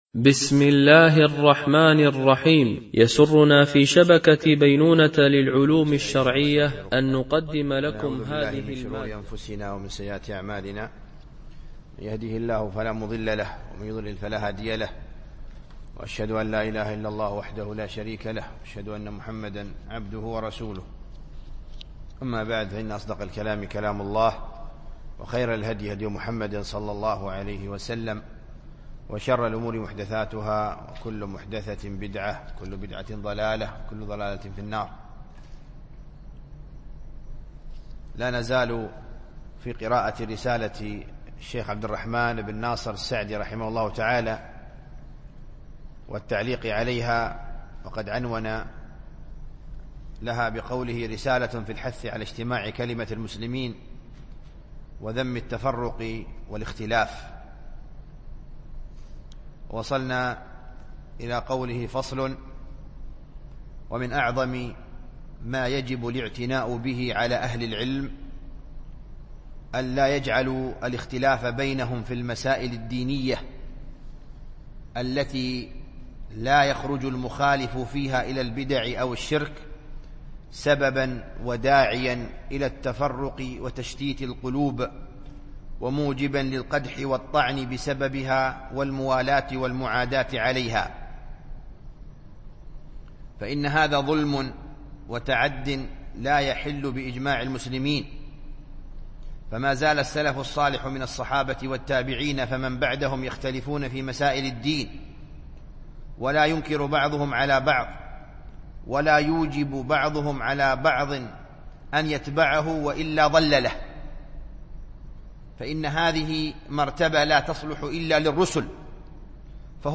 التنسيق: MP3 Mono 22kHz 32Kbps (CBR)